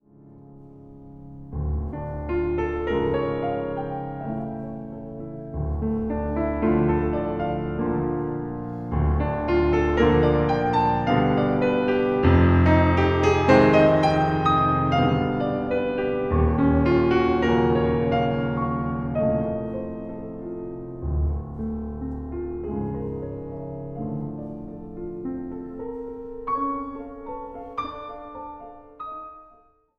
Instrumentaal | Piano
piano